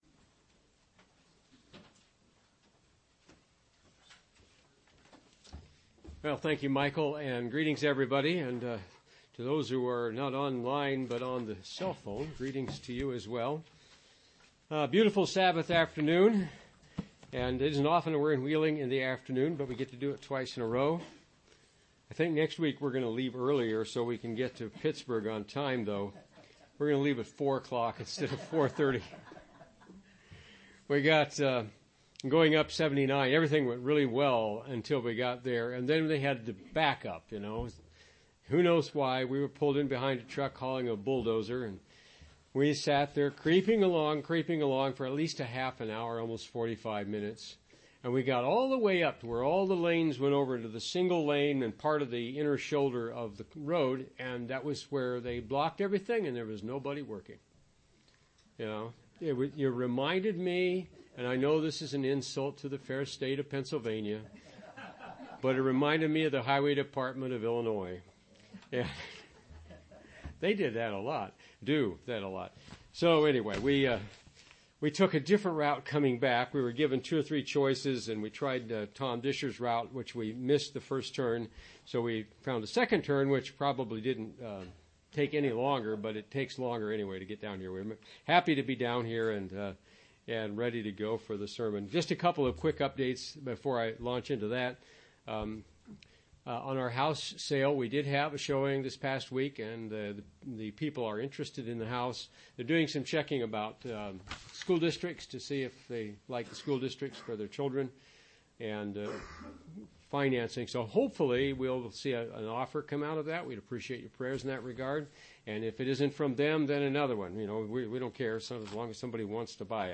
Given in Wheeling, WV
UCG Sermon Studying the bible?